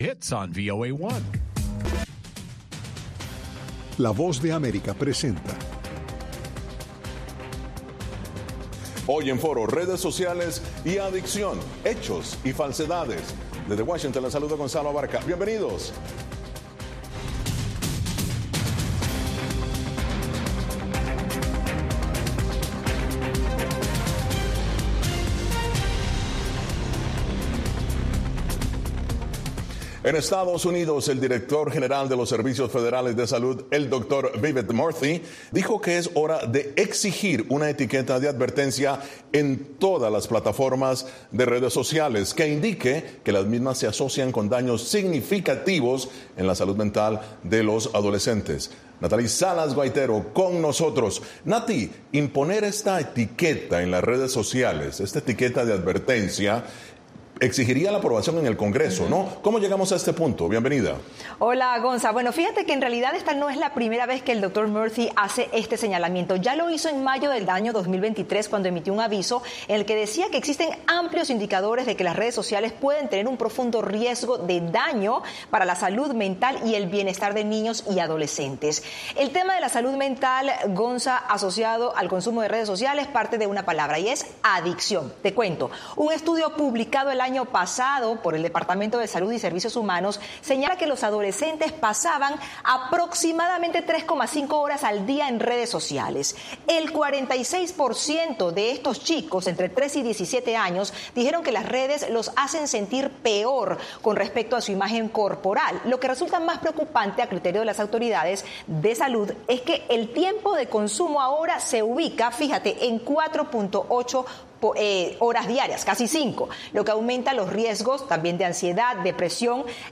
El punto de encuentro para analizar y debatir, junto a expertos, los temas de la semana.